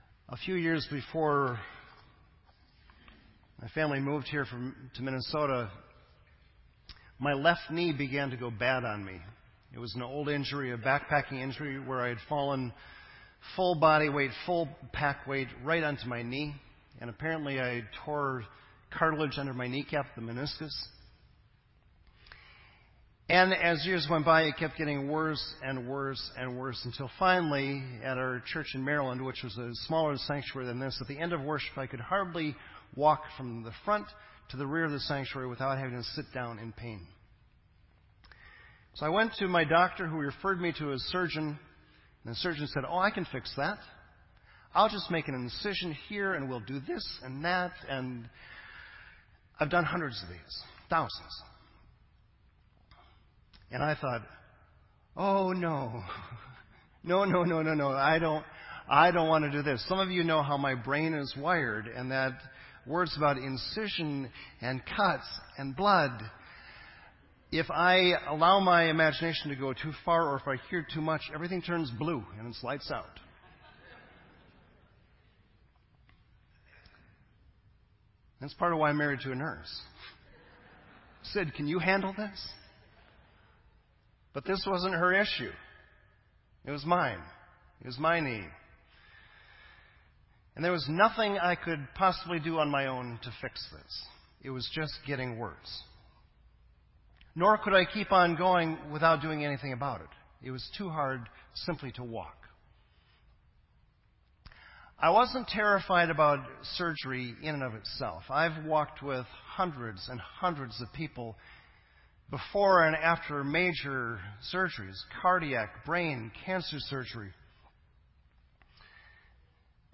November 8, 2015 sermon